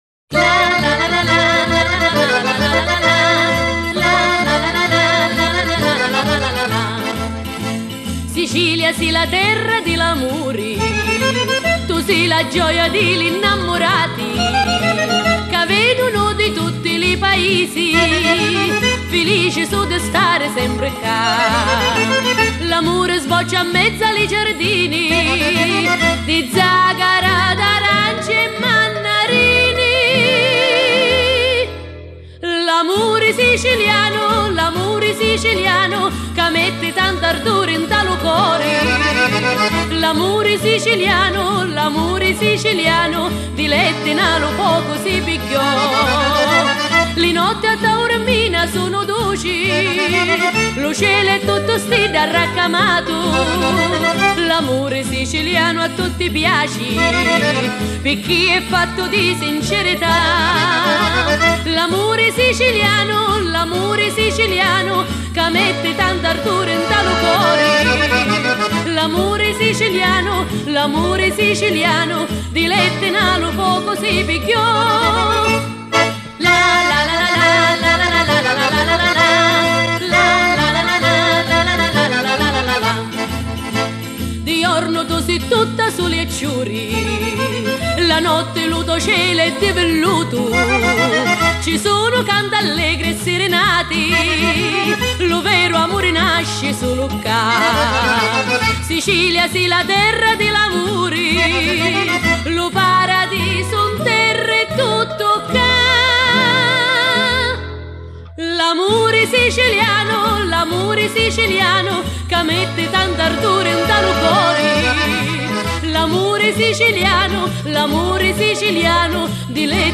ترانه ایتالیایی